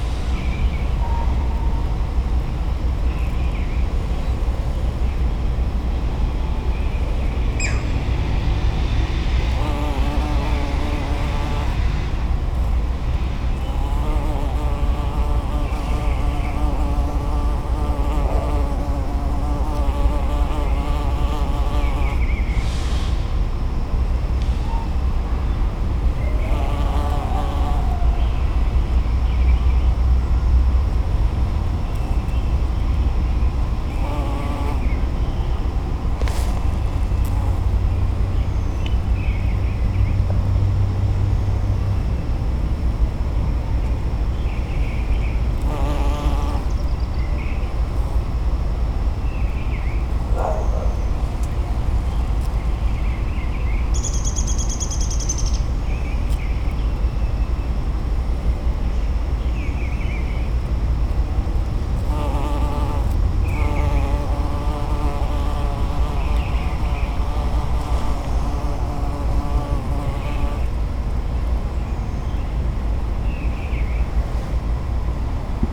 Common eastern bumble bee
In case you’re wondering, the sounds of the bee were bracketed by a Green Heron flight call and a Downy Woodpecker “whinny.” You also heard the sounds of an Eastern Bluebird and the neighbor’s dog.
I later followed the bee around with my shotgun mic.
bumble-bee.wav